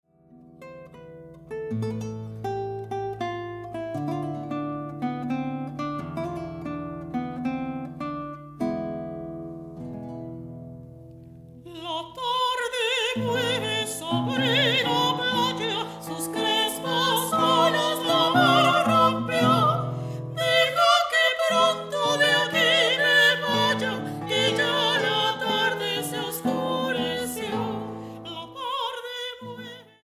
Grabado en la Sala Julián Carrillo de Radio UNAM